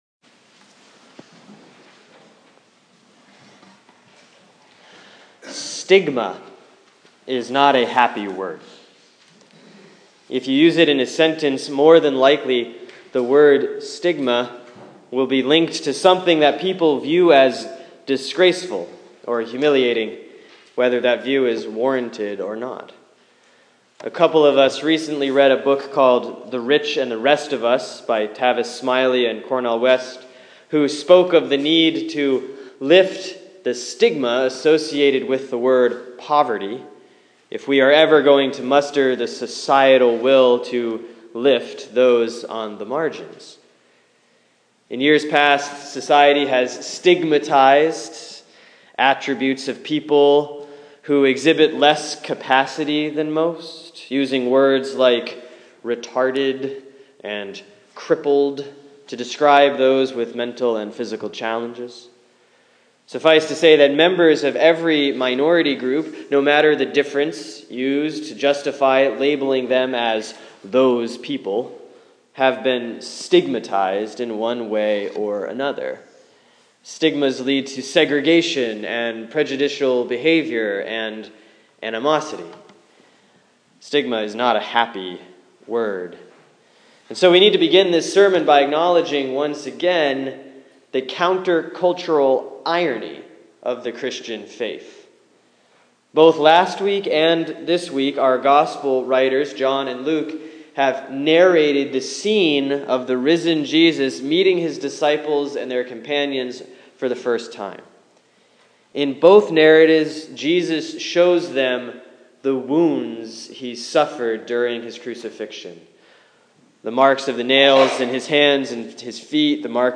Sermon for Sunday, April 19, 2015 || Easter 3B || Luke 24:36b-48